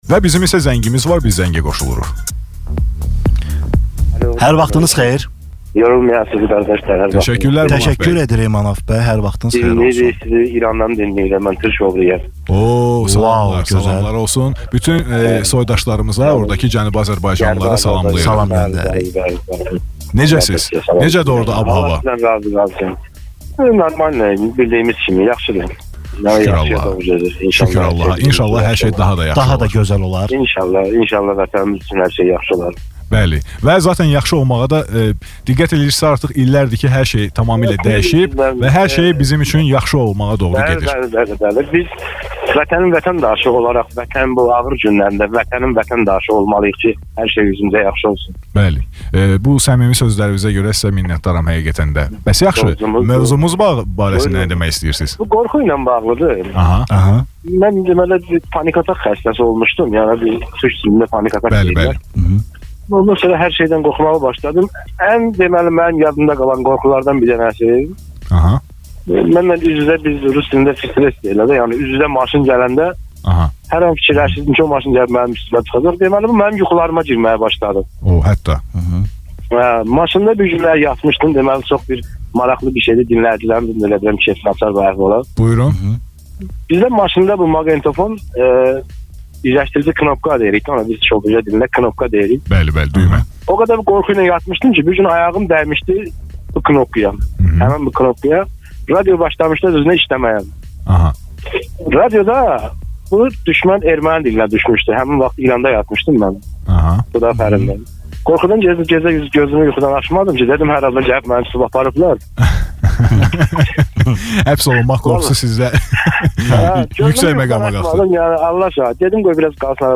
Xüsusilə Cənubi Azərbaycandan olan soydaşımızın zəngi diqqət çəkib və duyğusal anların yaşanmasına səbəb olub. Belə ki, soydaşımız verilişi maraqla dinlədiyini  dedikdən sonra öz avtomobilində daima Azərbaycan bayrağını daşıdığını bildirib.